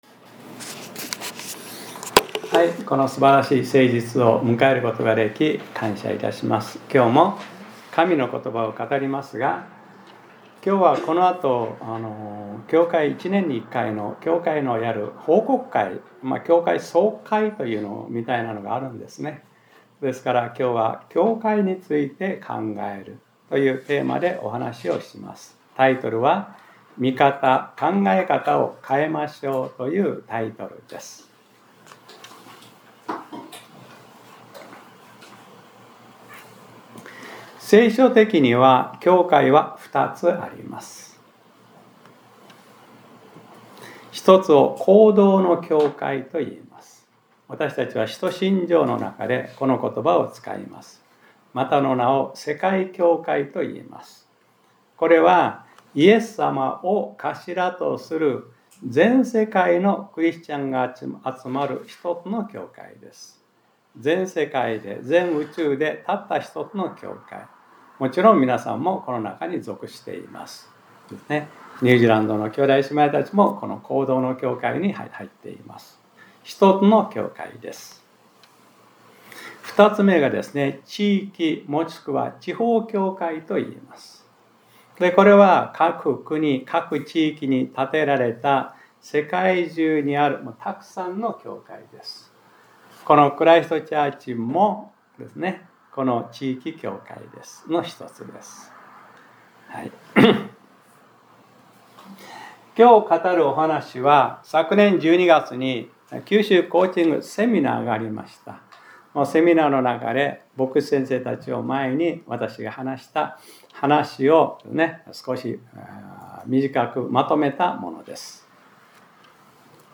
2026年02月01日（日）礼拝説教『 見方を変えなさい 』